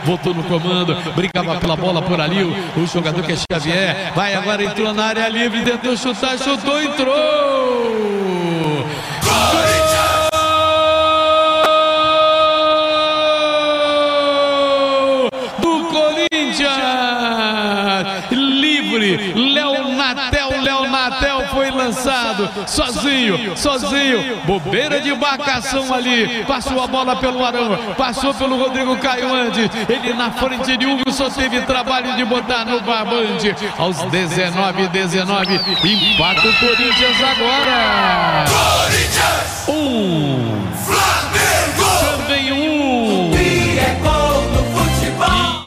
Ouça os gols da vitória do Flamengo sobre o Corinthians com a narração de José Carlos Araújo - Super Rádio Tupi
GOL-FLAMENGO-1-X-1-CORINTHIANS.mp3